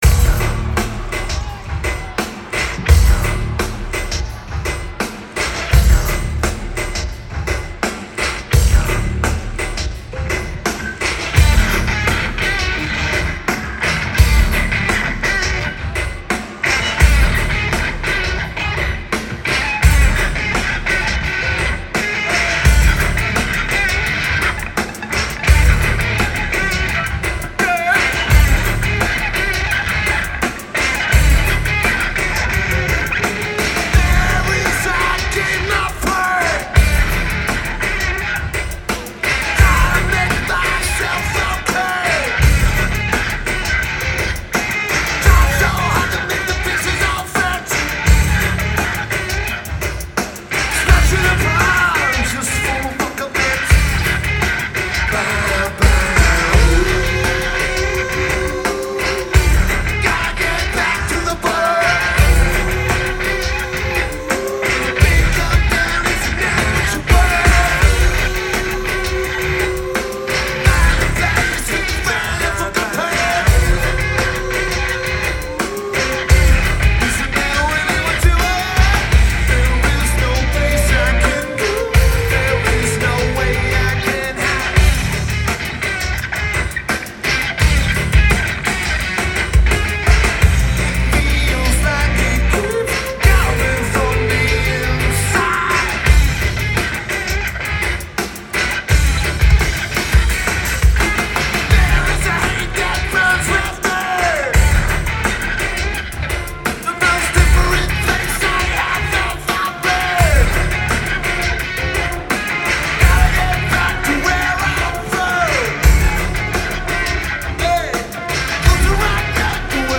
Rupp Arena
Lineage: Audio - AUD (DPA 4060 + Sony NH900)